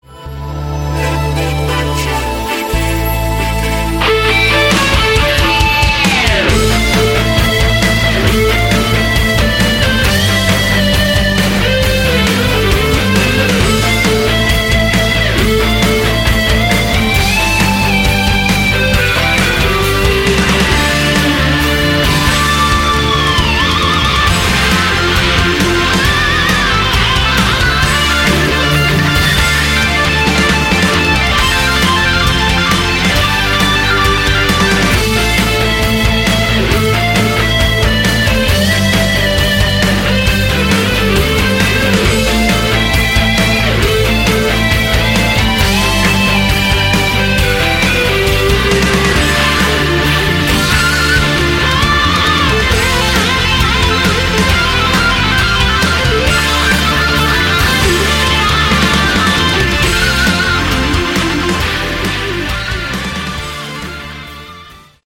Category: AOR
Lead & Backing Vocals
Drums
Keyboards
Keyboards, Bass, Acoustic Guitar, Backing Vocals
Rhythm, Solo & Acoustic Guitars
Bass